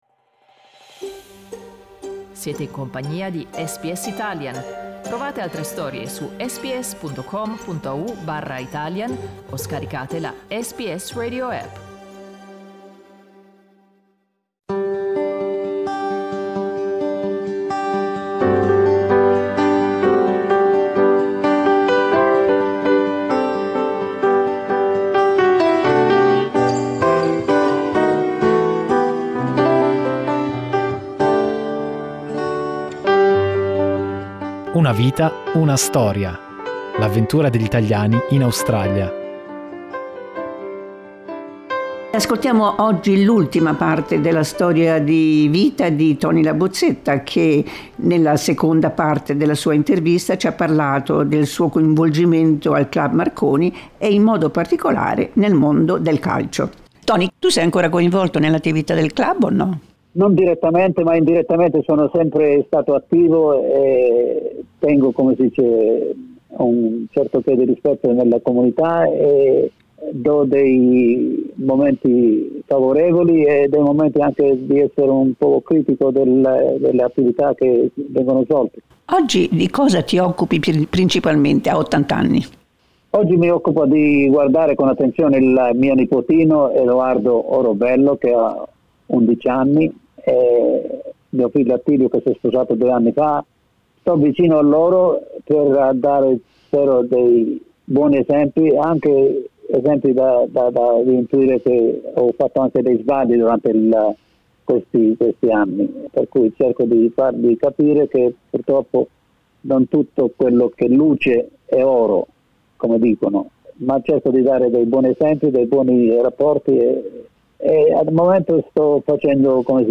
Terza e ultima parte dell'intervista